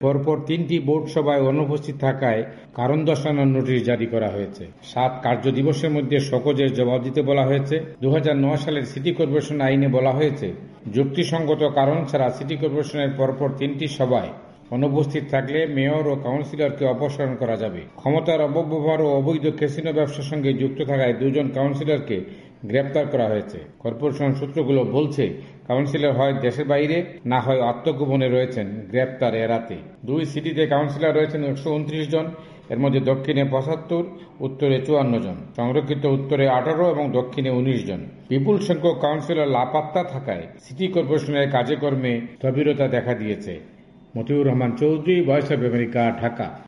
বিস্তারিত জানাচ্ছেন ঢাকা থেকে আমাদের সংবাদদাতা